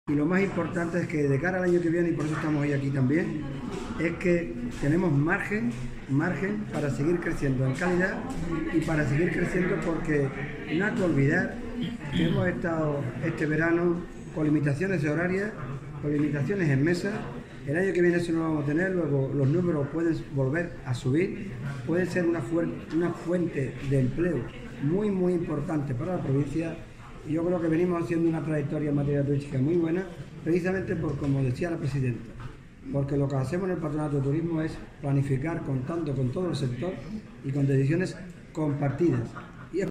Dia-del-turismo_Jose-Maria-Roman_a-medios.mp3